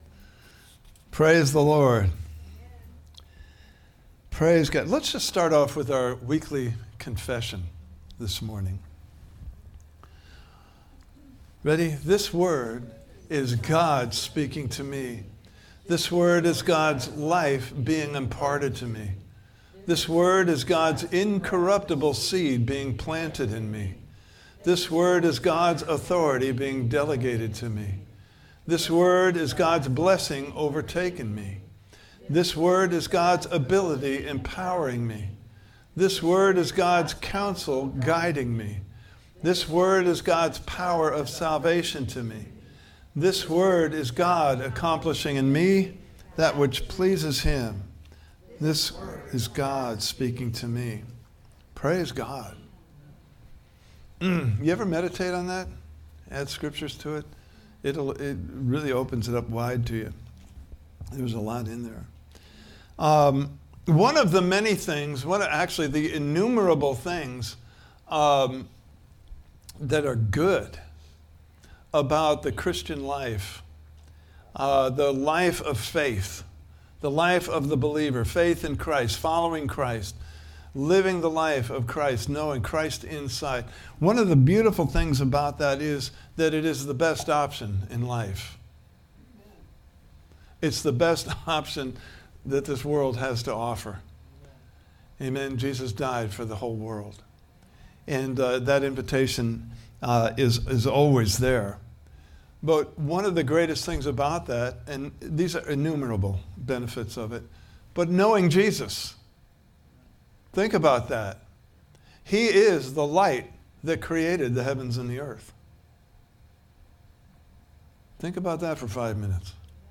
Series: Declaring the End from the Beginning! Service Type: Sunday Morning Service « Where Would You Like to be This Time Next Year?